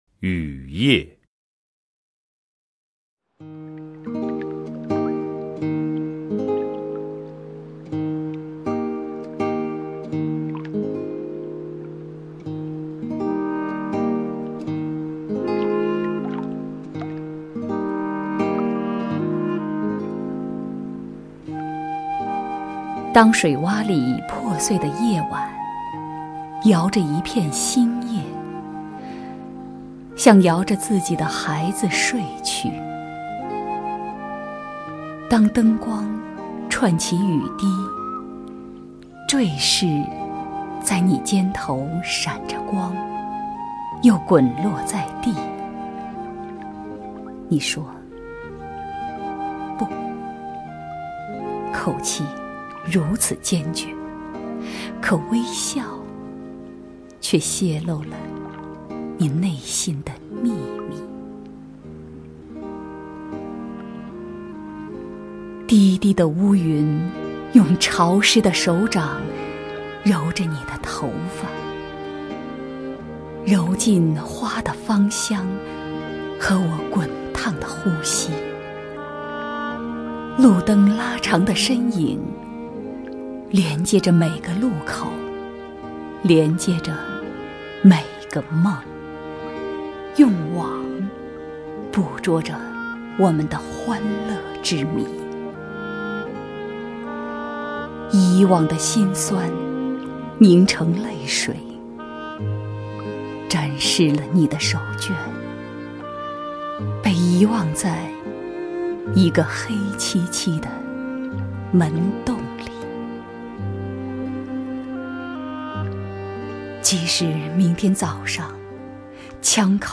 首页 视听 名家朗诵欣赏 狄菲菲
狄菲菲朗诵：《雨夜》(北岛)　/ 北岛